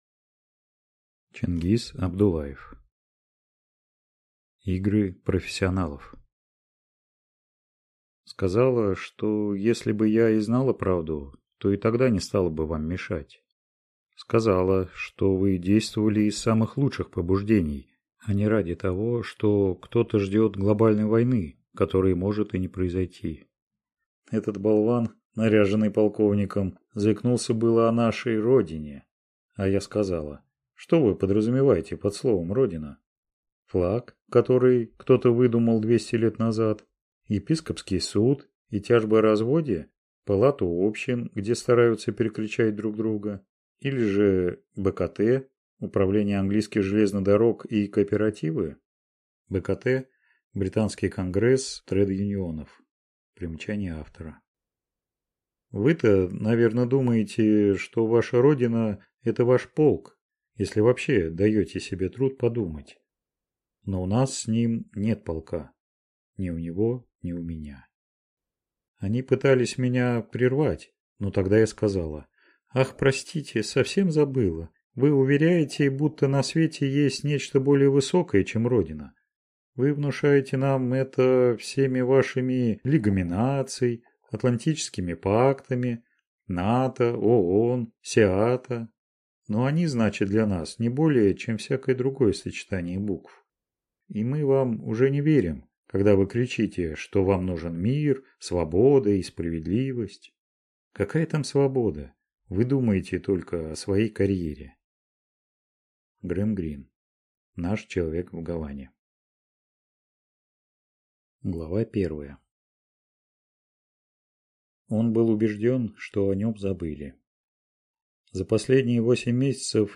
Аудиокнига Игры профессионалов | Библиотека аудиокниг